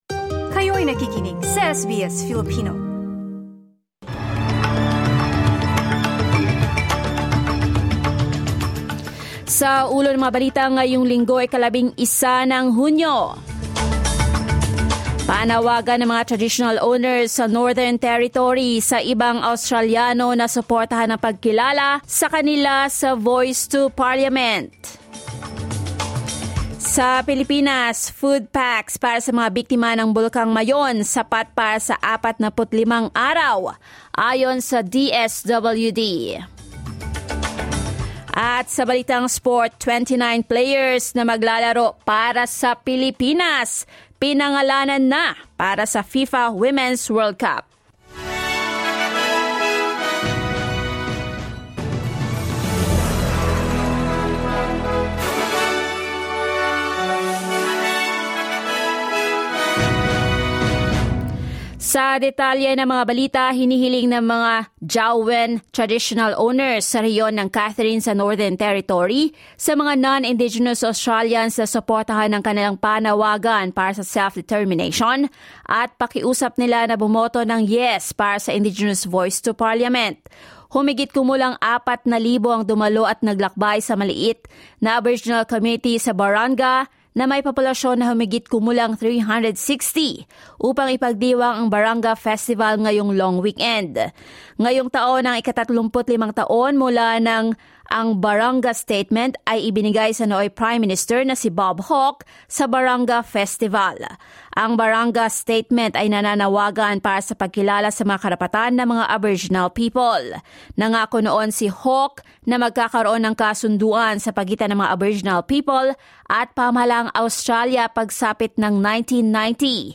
SBS News in Filipino, Sunday 11 June